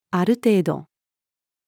ある程度-female.mp3